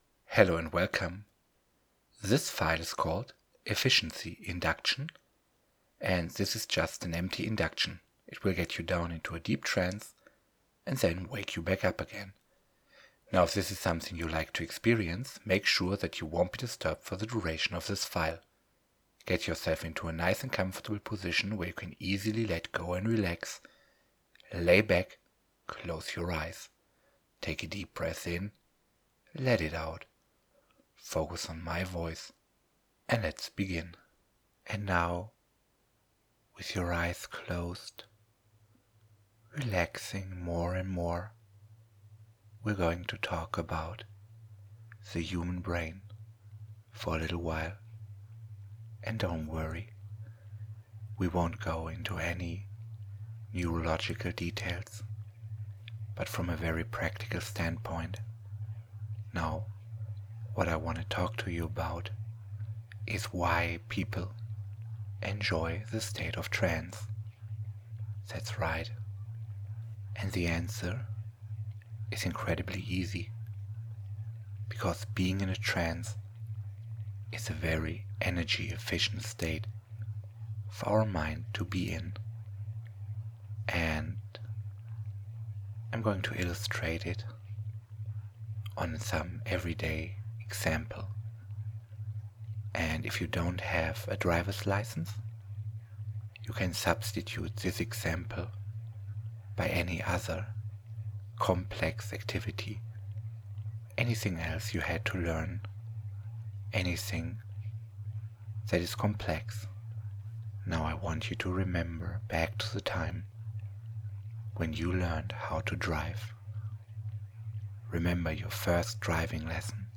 Efficiency Induction to get you down into a deep Hypnosis - Free Recording